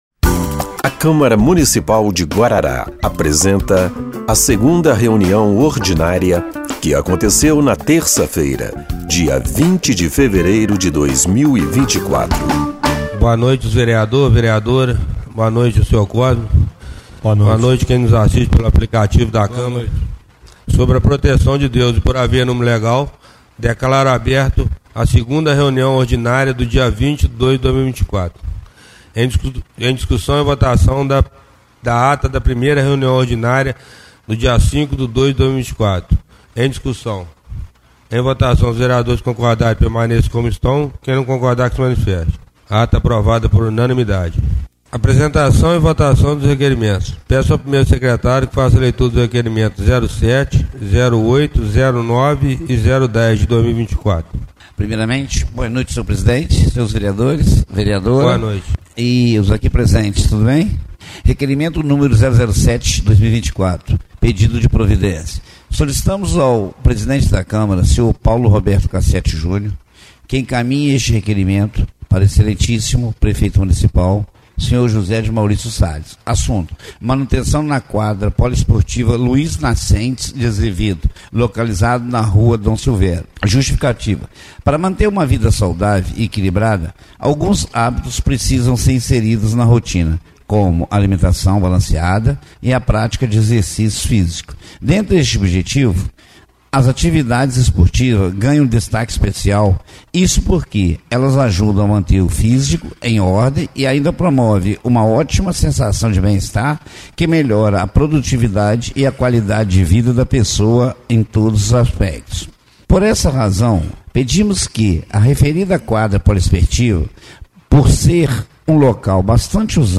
2ª Reunião Ordinária de 20/02/2024